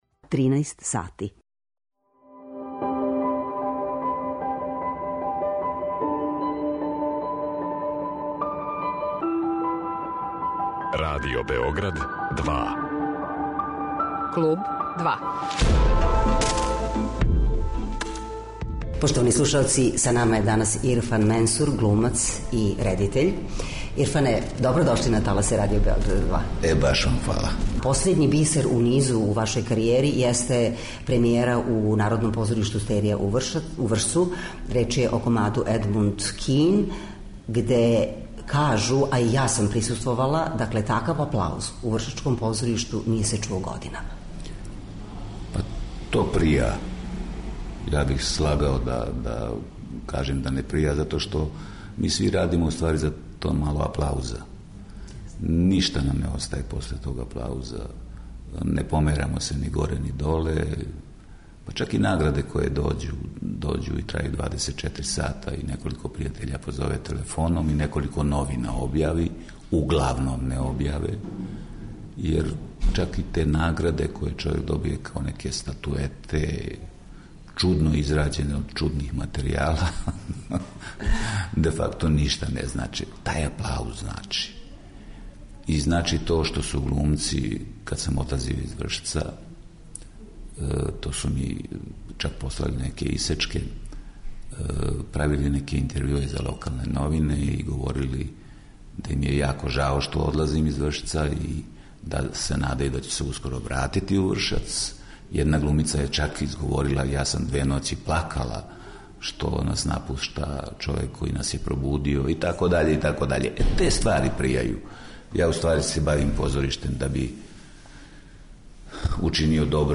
Гост емисије је Ирфан Менсур, глумац и редитељ.